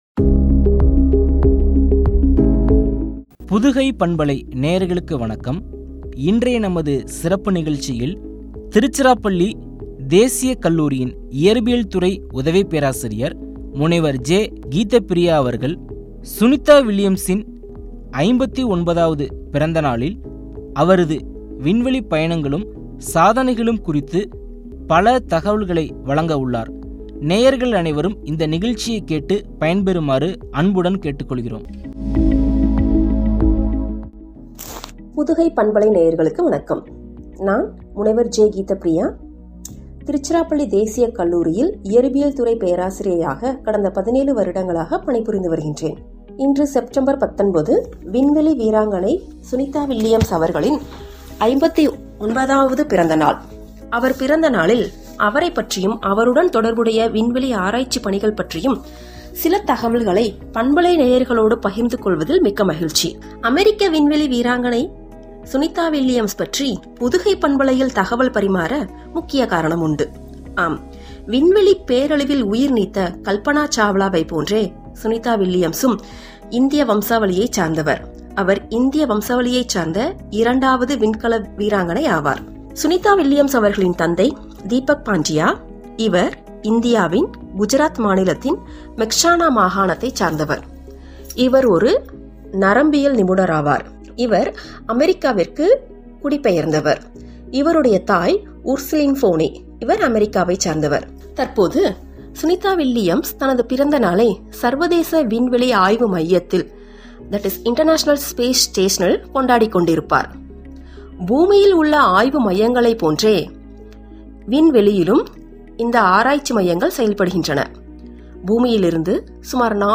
சாதனைகளும்” குறித்து வழங்கிய உரையாடல்.